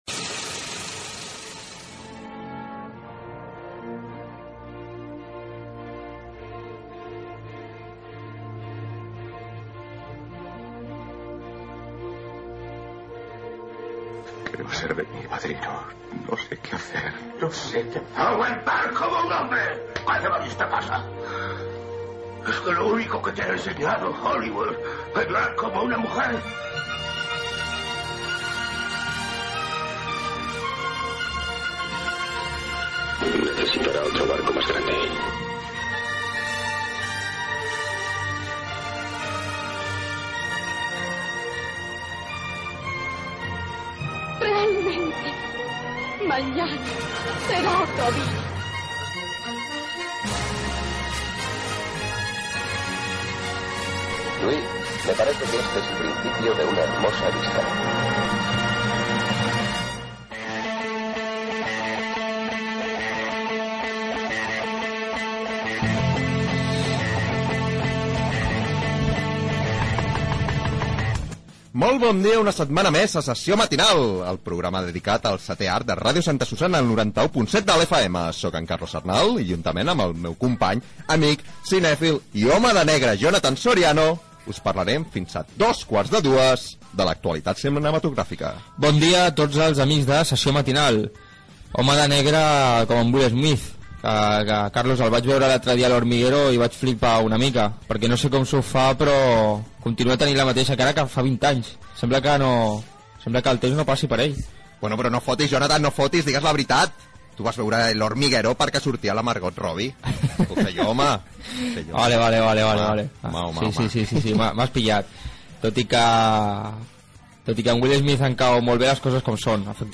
Fragment de pel·lícula, identificació del programa i de l'emissora, comentari d'una entrevista feta al programa "El hormiguero", invitació a la participació, sumari de continguts.